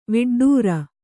♪ viḍḍūra